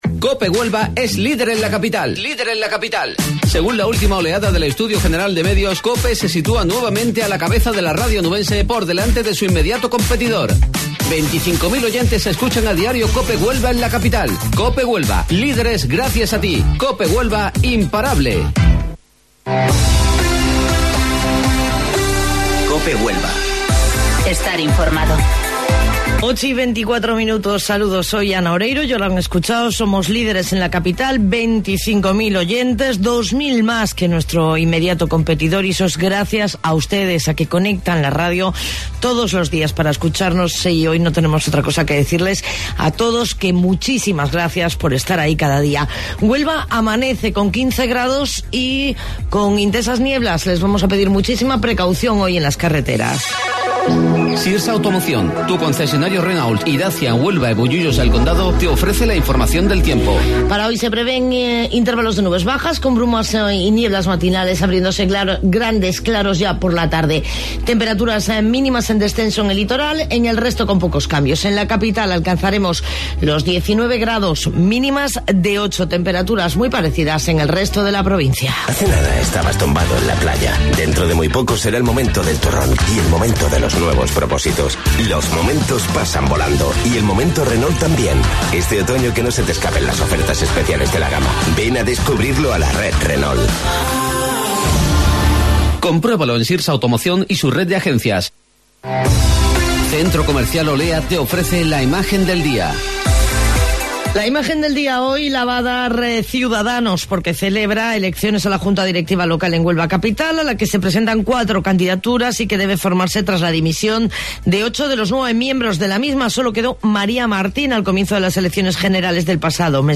AUDIO: Informativo Local 08:25 del 28 de Noviembre